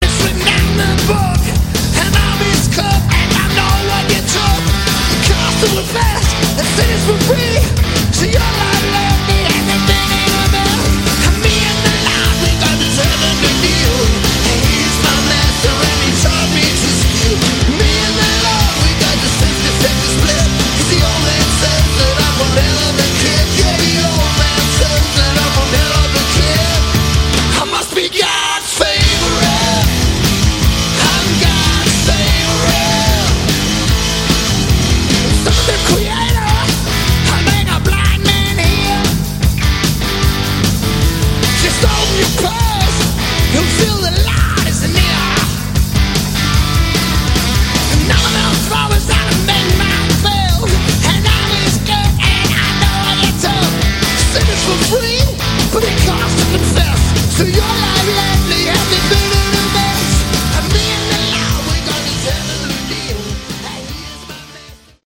Category: Hard Rock
Vocals, Guitar
Drums
Bass
live